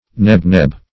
neb-neb - definition of neb-neb - synonyms, pronunciation, spelling from Free Dictionary Search Result for " neb-neb" : The Collaborative International Dictionary of English v.0.48: Neb-neb \Neb"-neb`\, n. Same as Bablh .